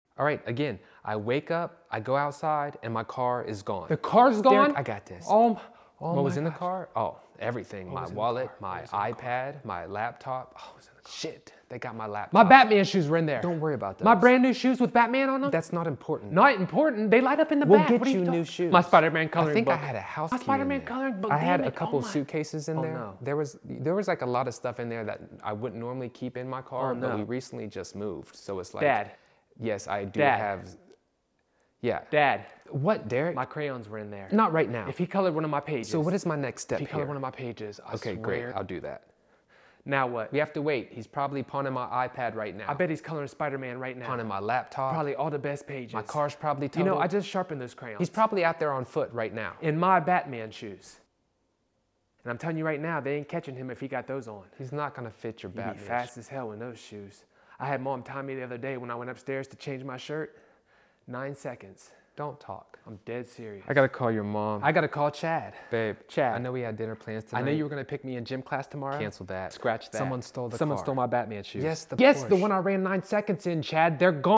talking dogs with human voice